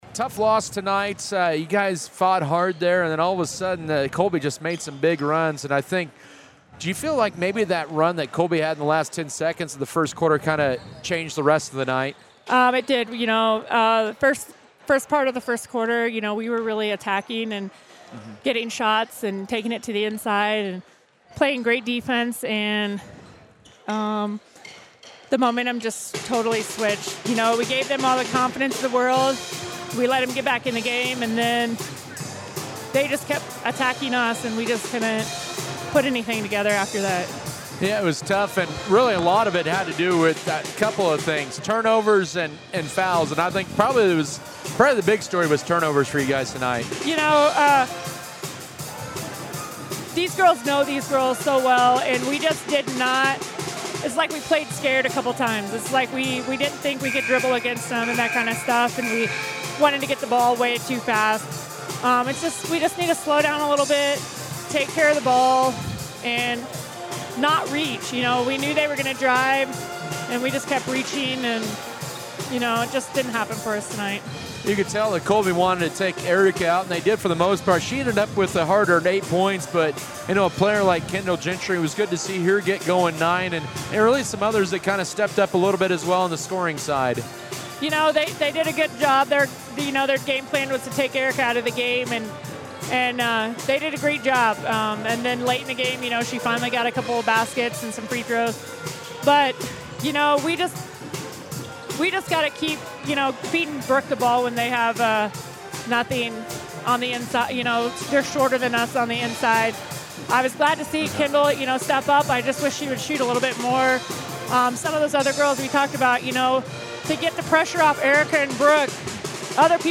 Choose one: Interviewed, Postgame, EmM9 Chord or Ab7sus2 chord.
Postgame